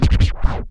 scratch04.wav